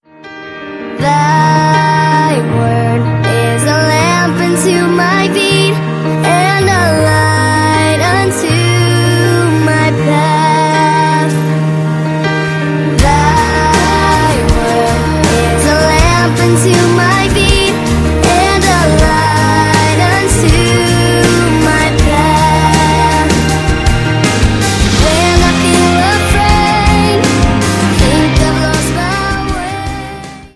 Performance, aktuellen Charts-Pop;
• Sachgebiet: Pop